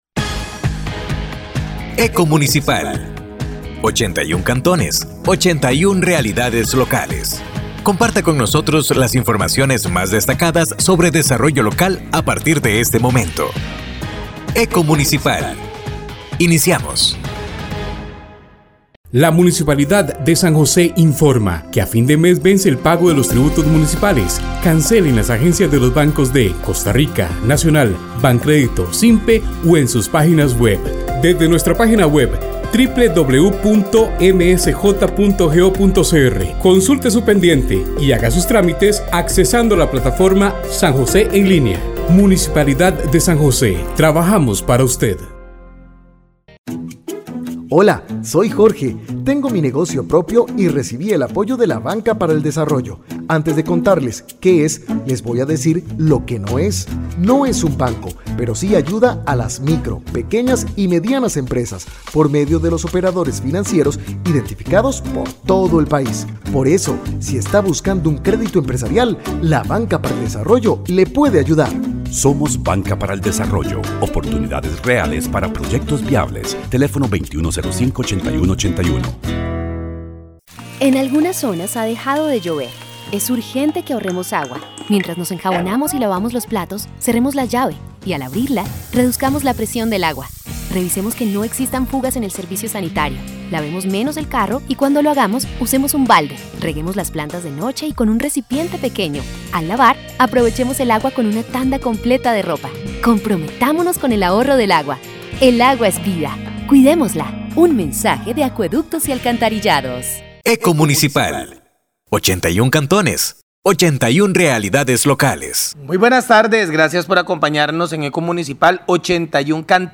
Programa de Radio Eco Municipal